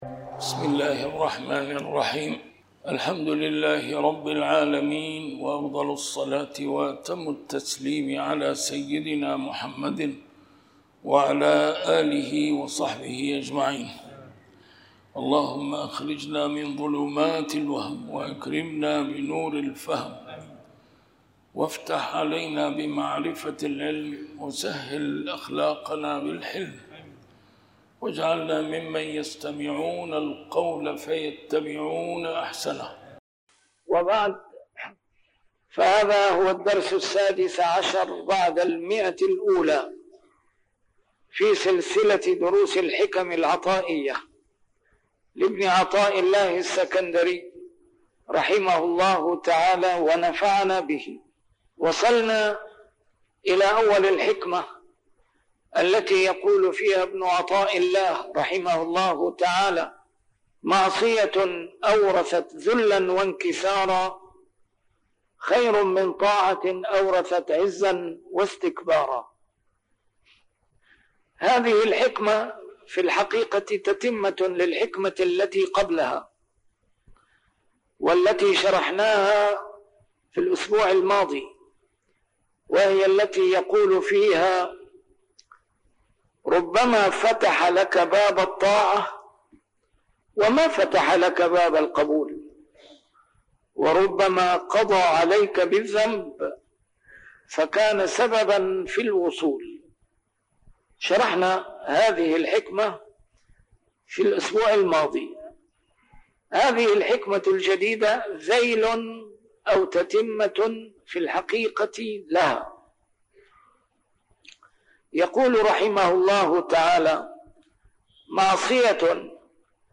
الدرس رقم 116 شرح الحكمة 96